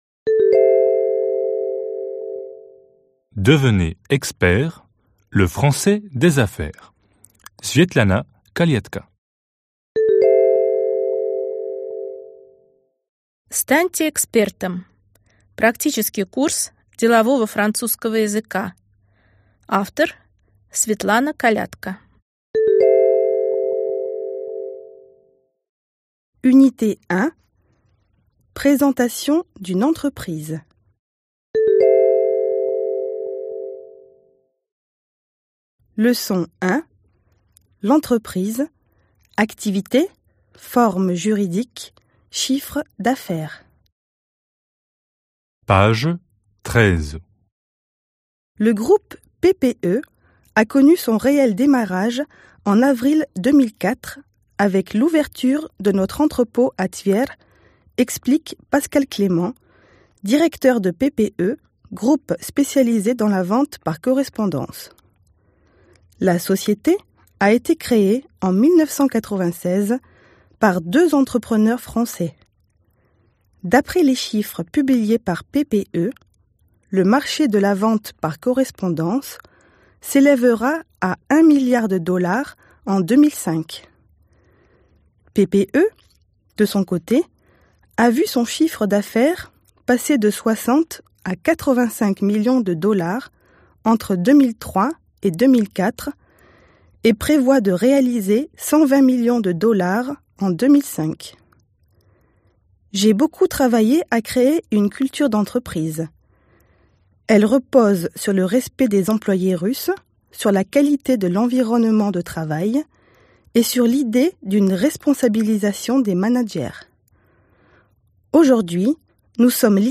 Аудиокнига Станьте экспертом! Практический курс делового французского языка | Библиотека аудиокниг